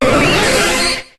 Cri de Staross dans Pokémon HOME.